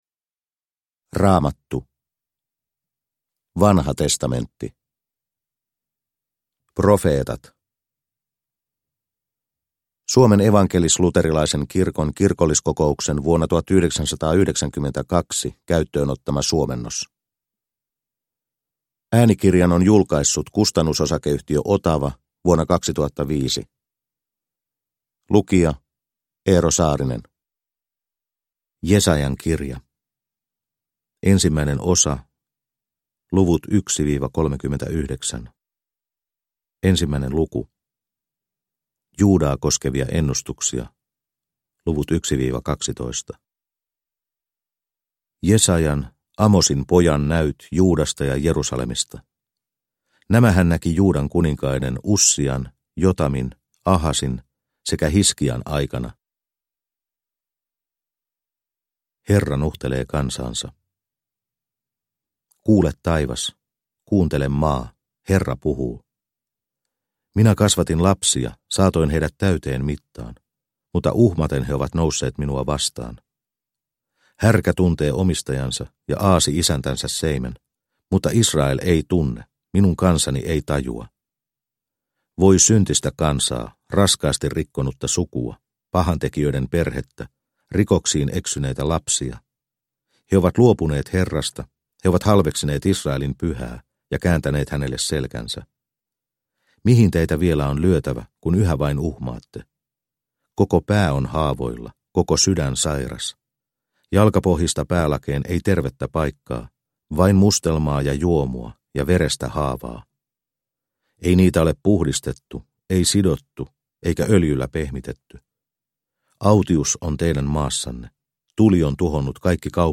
Vanha testamentti äänikirjana